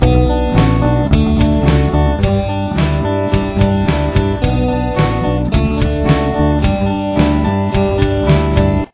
Verse Riff